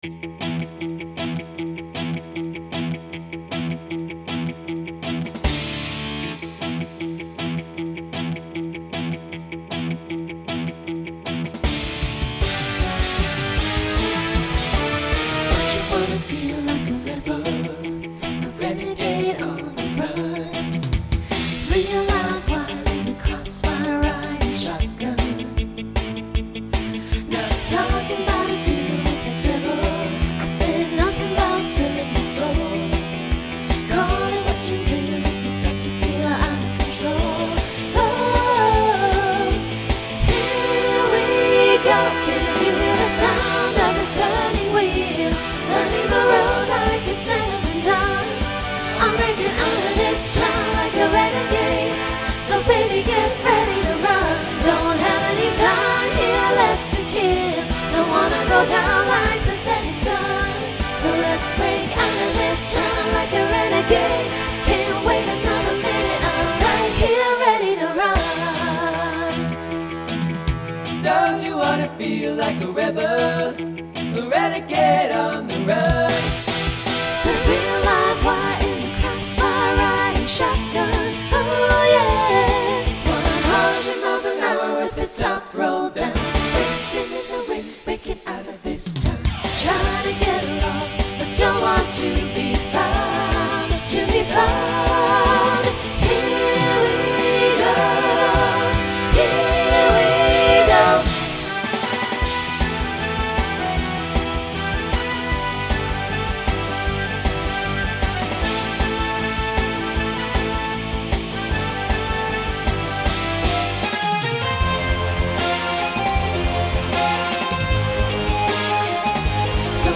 New SSA voicing for 2026.